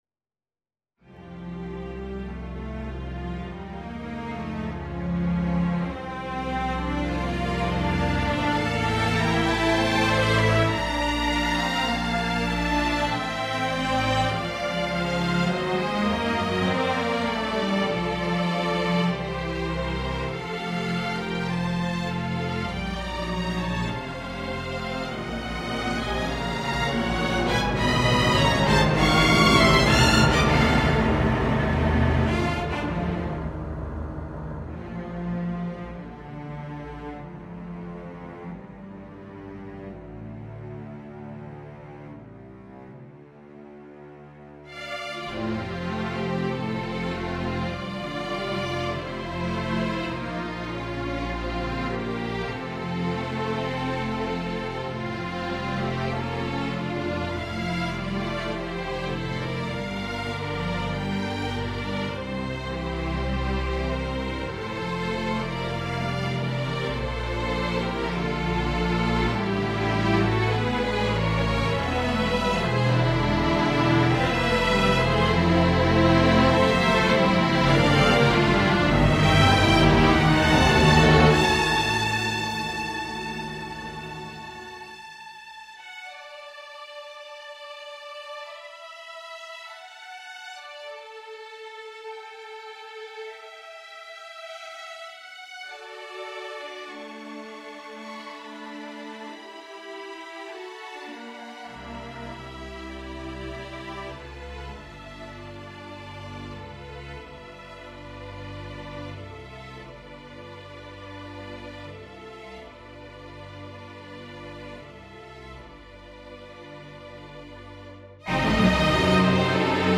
Spiritual warfare - Orchestral and Large Ensemble
I ended up writing a string orchestra piece with some interesting harmonies and repetitive melodic shape.